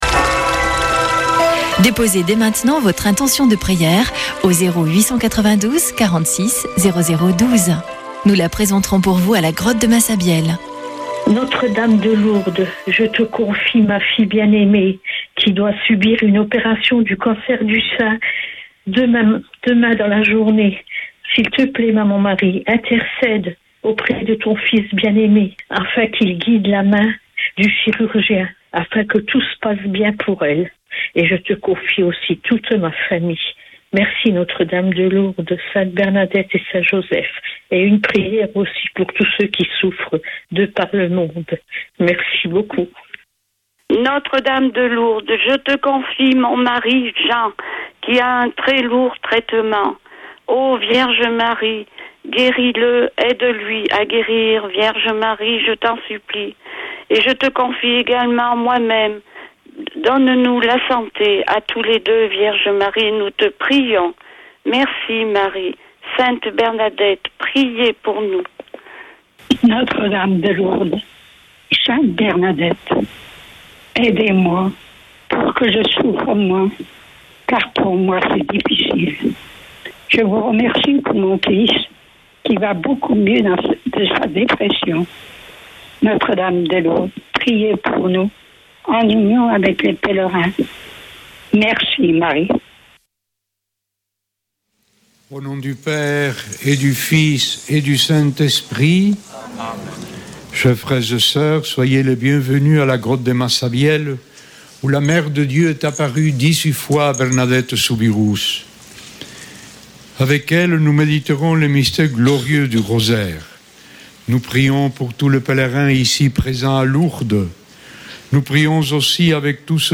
Chapelet de Lourdes du 21 janv.
Une émission présentée par Chapelains de Lourdes